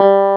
CLAV2SFTG3.wav